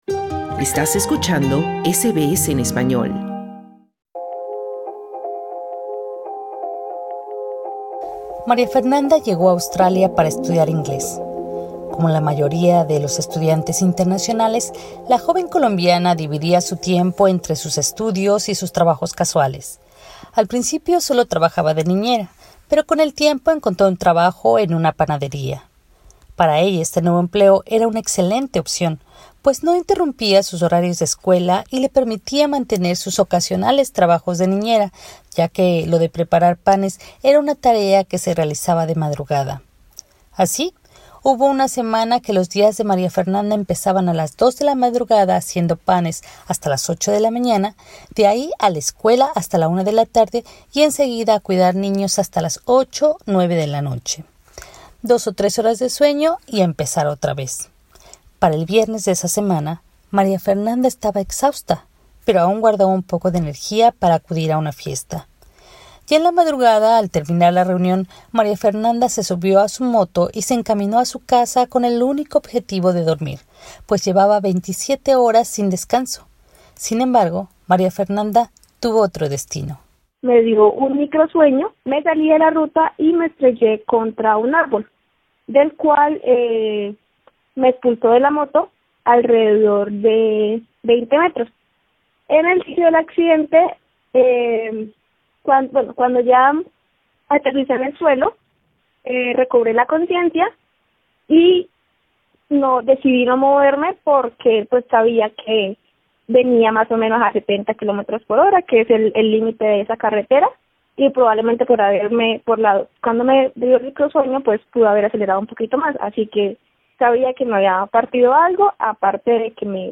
Escucha en nuestro podcast dos testimonios de personas que sufrieron un accidente en Australia, cómo se resolvió su caso y cuáles son los recursos a los que se puede recurrir en estas situaciones.